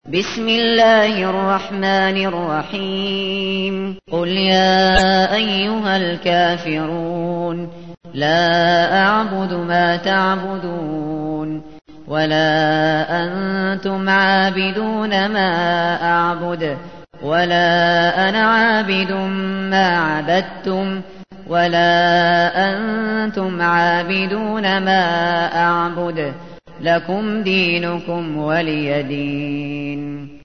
تحميل : 109. سورة الكافرون / القارئ الشاطري / القرآن الكريم / موقع يا حسين